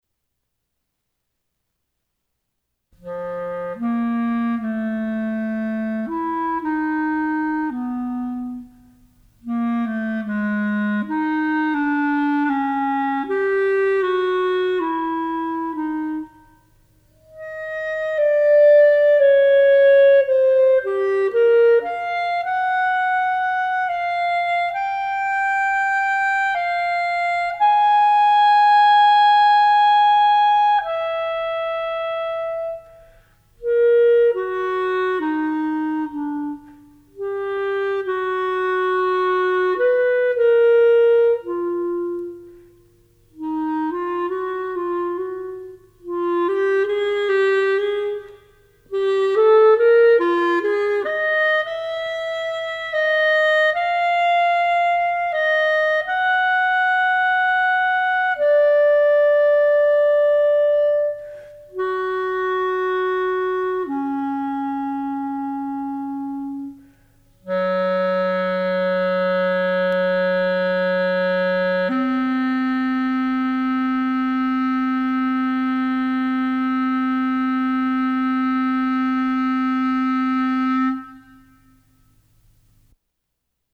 Voicing: Clarinet Method